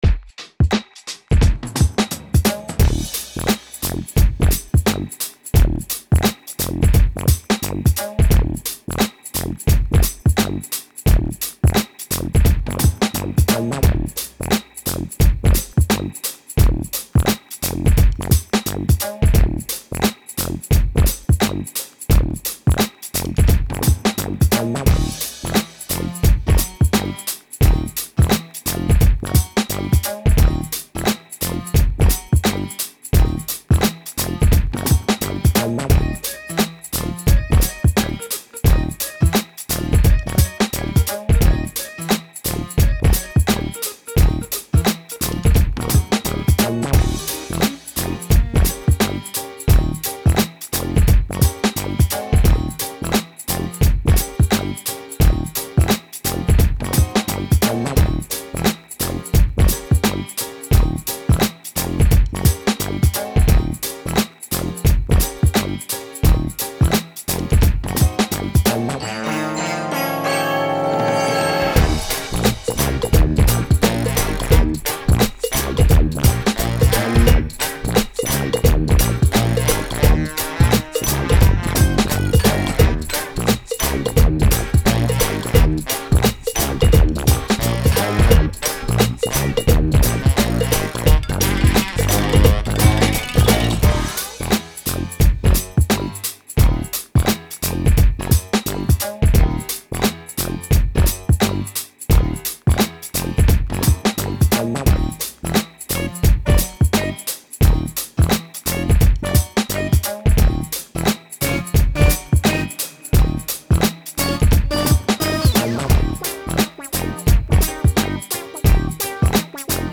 fantastic backing track for anyone who wants to rap along.
Instrumental Version